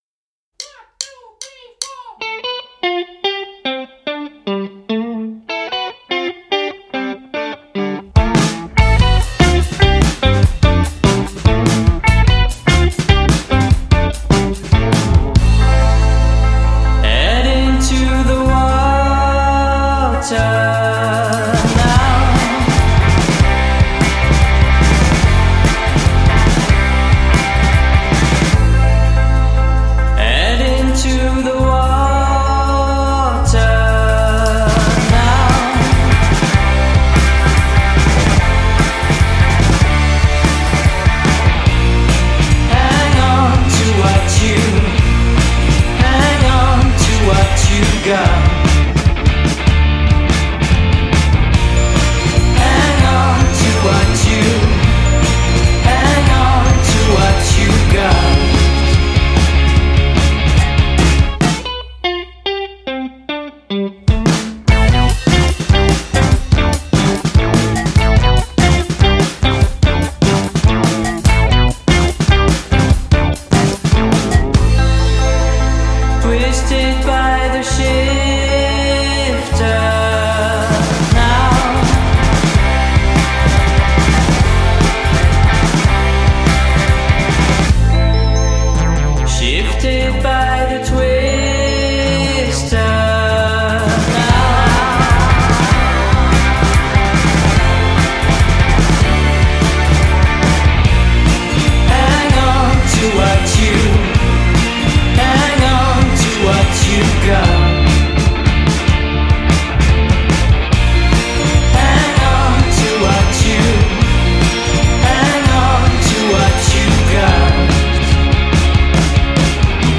Naviguant entre rock cosmique et électro-pop